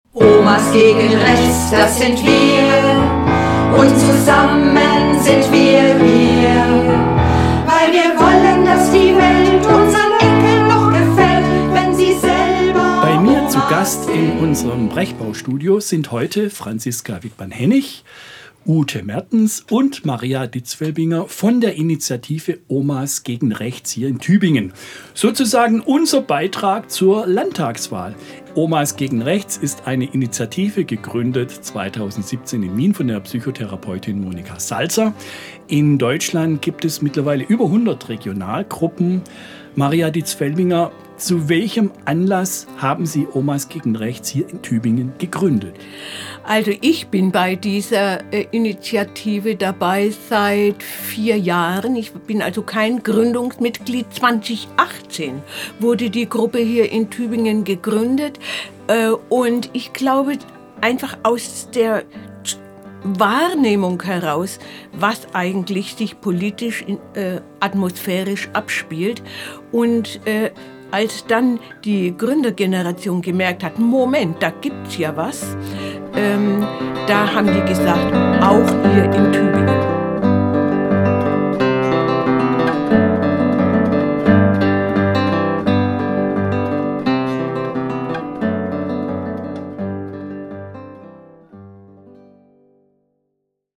Studiogespräch mit Omas gegen Rechts (683)
Und auch die Musik ist vom gleichnamigen Chor.
Sozusagen unser Beitrag zur Landtagswahl. Die Songs stammen vom Tübinger Chor der Omas gegen Rechts. Wir haben sie vorab in unserem Hörfunkstudio aufgenommen.